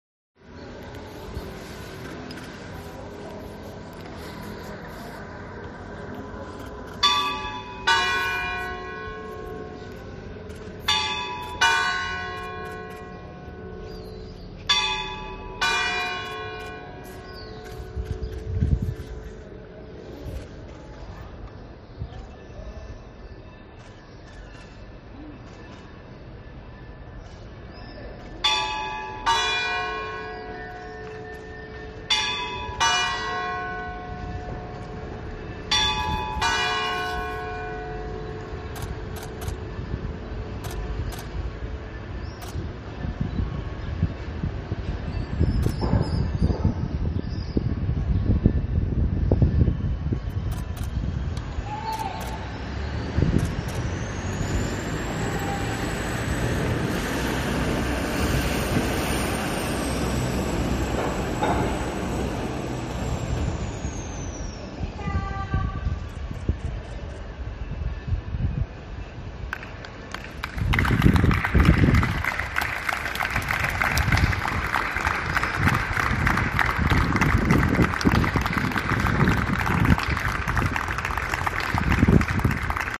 Minuto silencio fallecidos COVID19